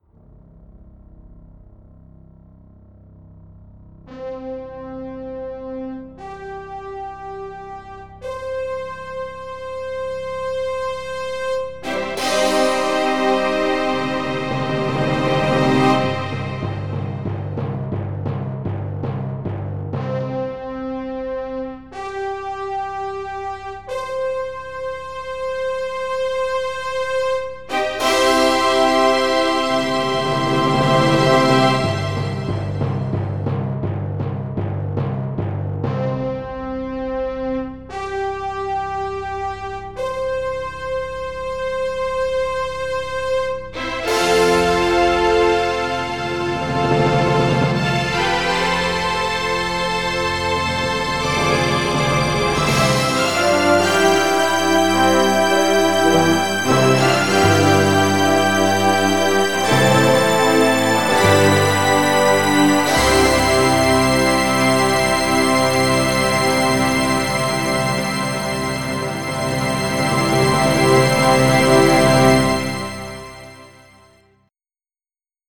MIDI Music File
General MIDI (type 1)